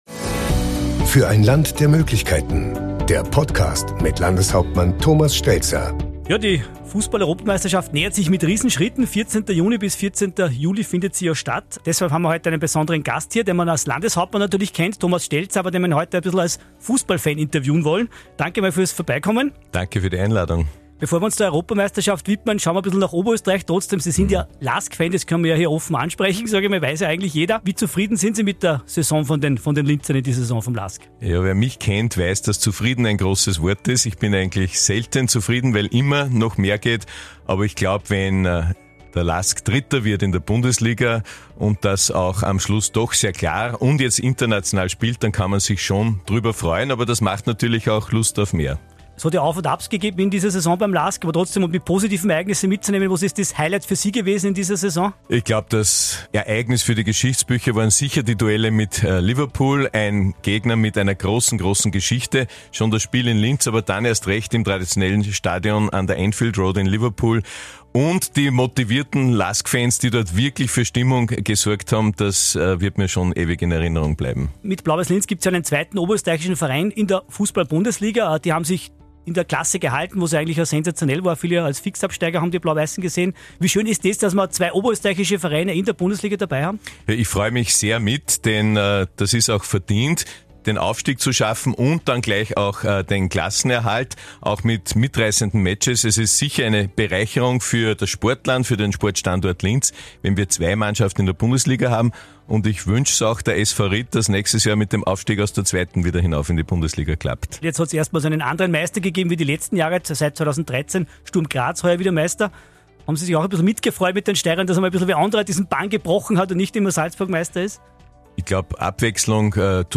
Thomas Stelzer im Gespräch